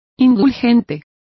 Complete with pronunciation of the translation of permissive.